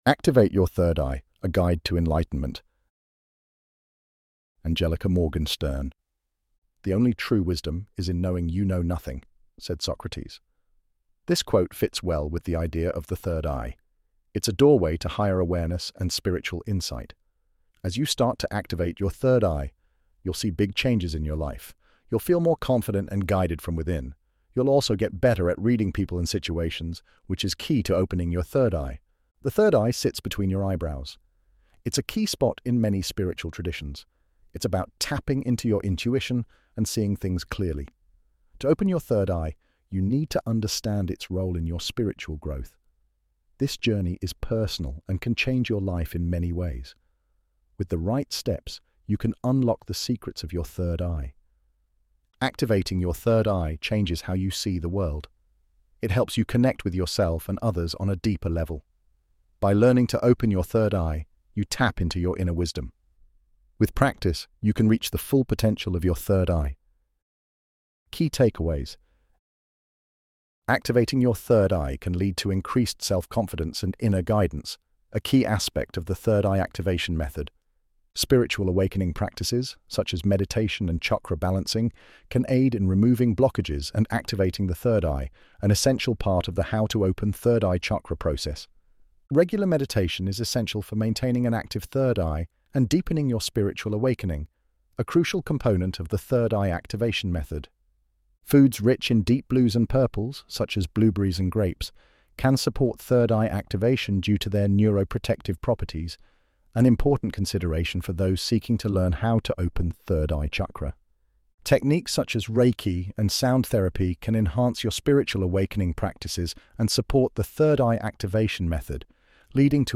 ElevenLabs_Activate_Your_Third_Eye_A_Guide_to_Enlightenment.mp3